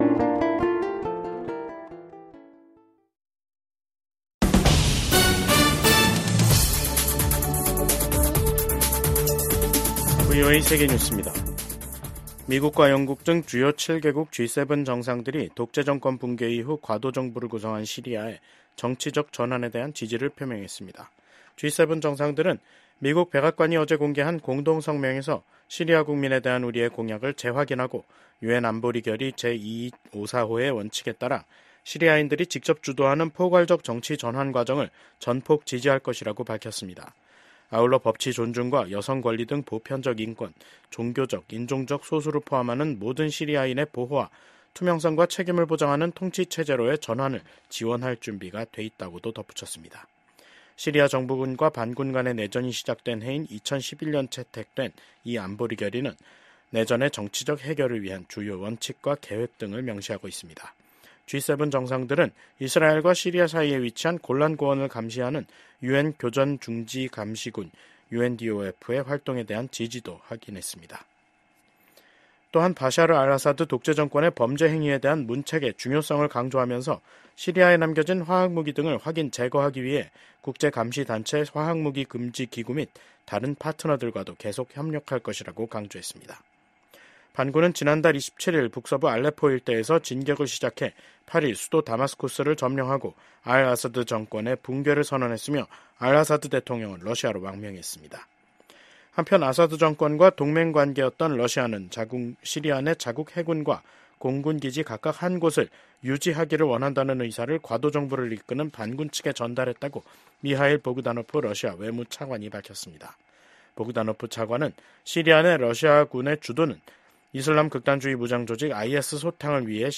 VOA 한국어 간판 뉴스 프로그램 '뉴스 투데이', 2024년 12월 13일 2부 방송입니다. 비상계엄 사태를 일으킨 윤석열 한국 대통령에 대한 탄핵소추안 2차 투표가 내일 진행됩니다. 미국의 전직 고위 관리들은 북한이 연일 한국 대통령의 비상 계엄 선포와 탄핵 정국을 보도하는 것은 한국 정부를 비난하고 미한 동맹을 약화시키려는 선전선동 목적이 크다고 진단했습니다.